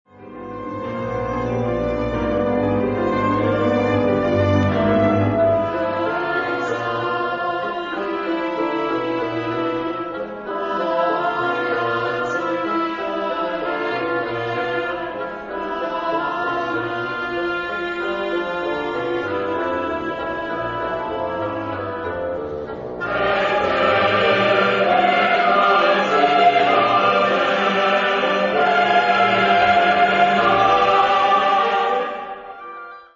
Genre-Stil-Form: Sinfonie ; geistlich ; Psalm
Chorgattung: SATB  (4 gemischter Chor Stimmen )
Instrumentation: Orchester